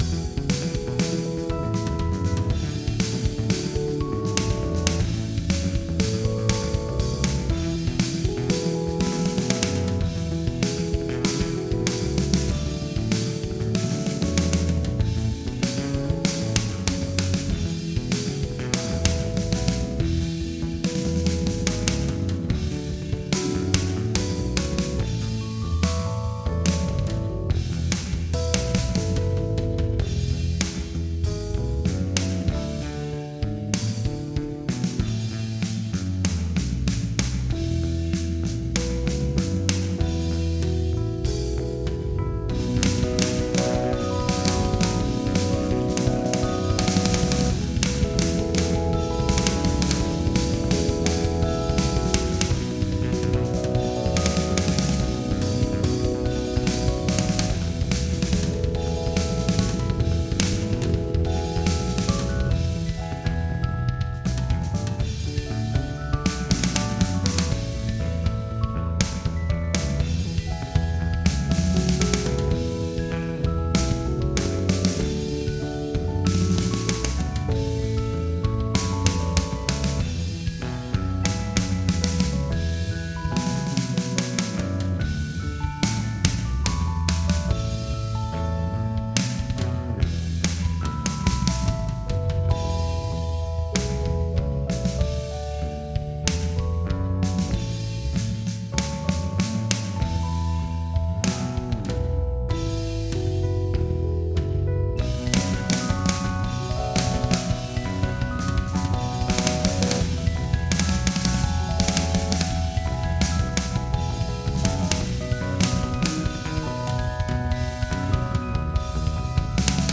#1 #2 #3 #4 Tempo Meter Chords
This means that despite this piece’s frequently changing tempi, its measures have a constant length of 2.5 seconds.